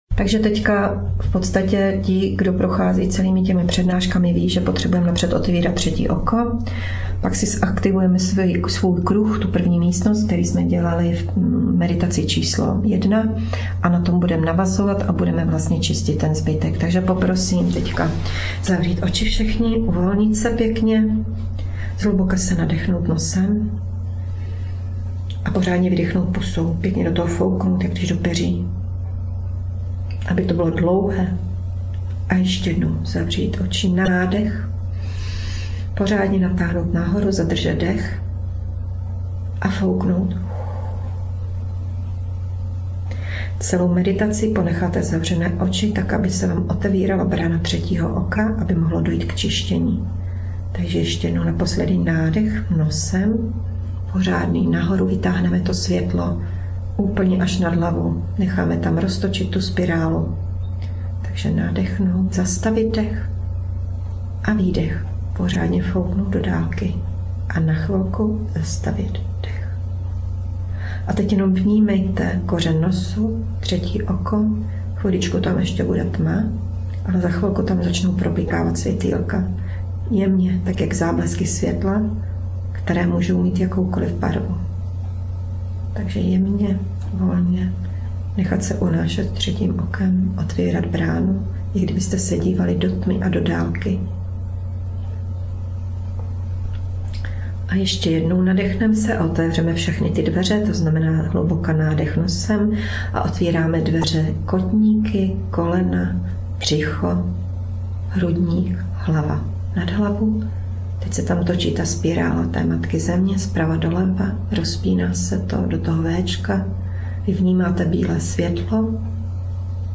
Na začátku meditace je úvodní slovo, aby jste pochopili o co jde, a uměli otevřít svou duši směrem ke světlu. V mém hlase je náboj světla, který Vám pomůže naladit se na frekvenci vyššího JÁ.